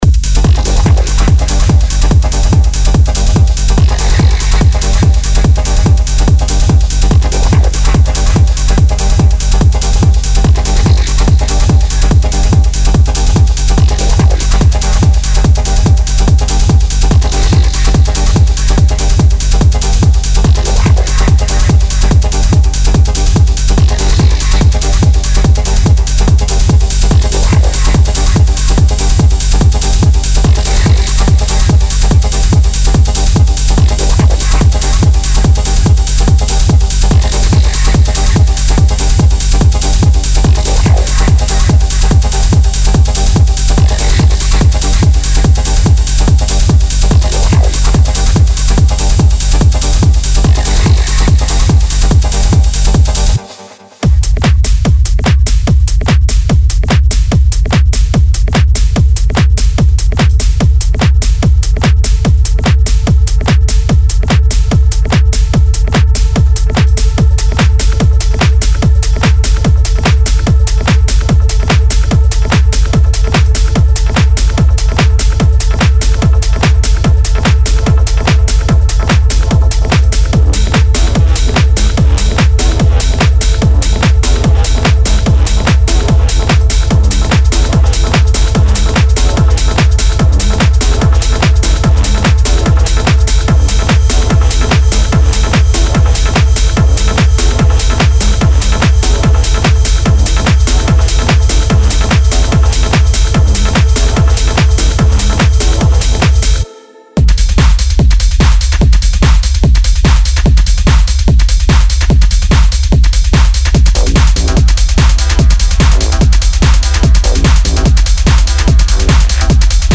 Want rolling, hypnotic loops and percussive beats that slap?